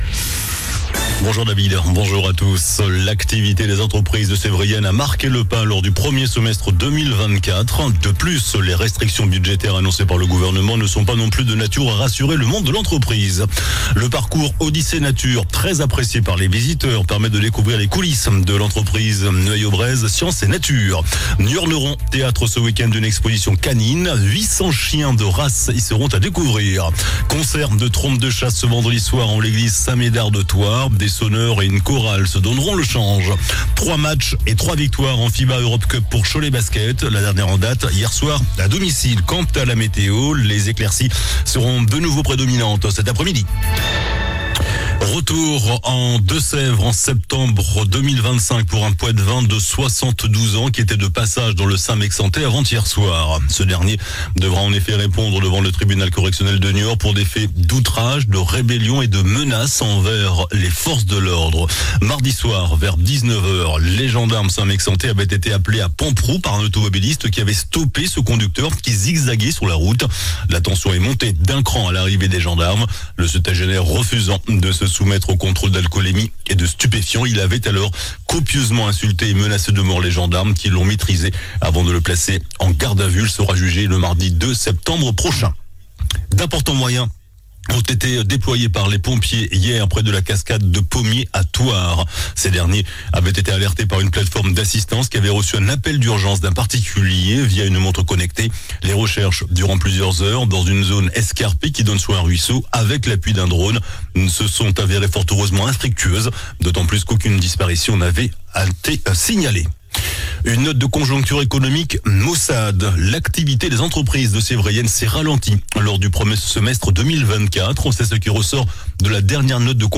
JOURNAL DU JEUDI 24 OCTOBRE ( MIDI )